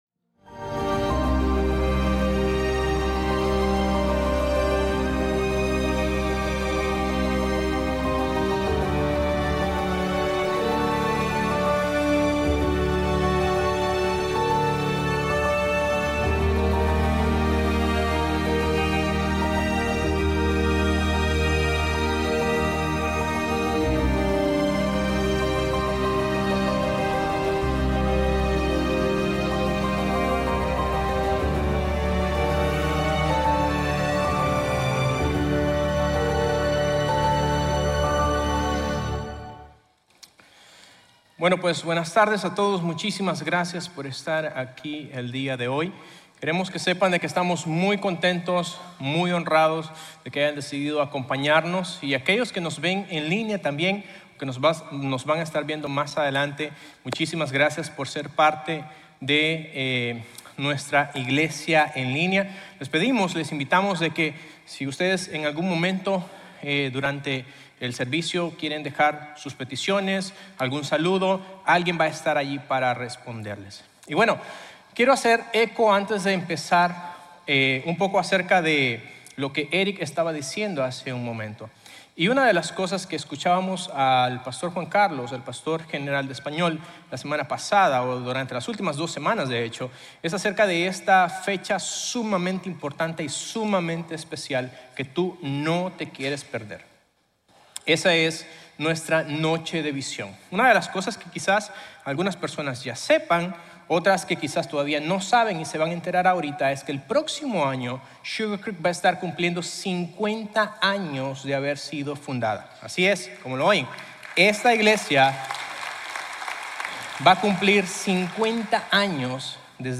En realidad, Dios te ha creado para ser una parte vital de Su cuerpo. En nuestro sermón “Esto es lo que somos”, exploramos cómo cada miembro es indispensable, cómo nuestra diversidad nos fortalece y cómo juntos somos Cristo en acción.